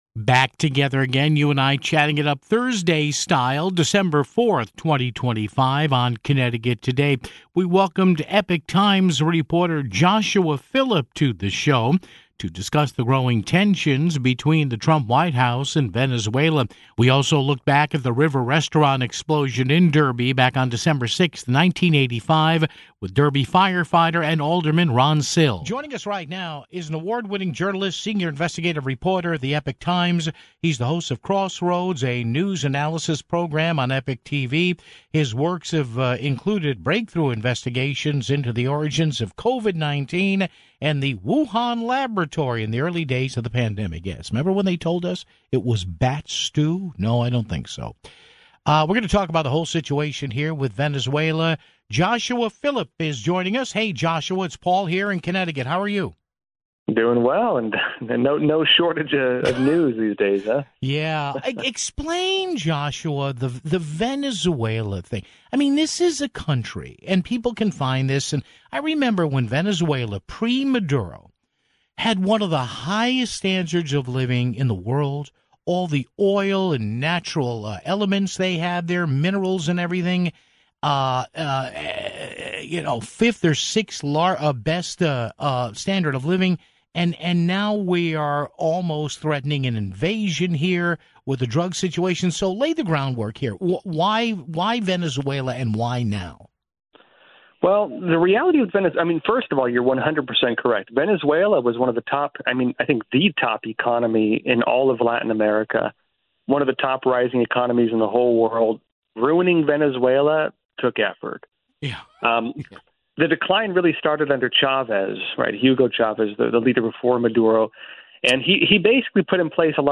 We also looked back at the River Restaurant explosion and fire in Derby on December 6th, 1985, with Derby firefighter and Alderman Ron Sill (09:57)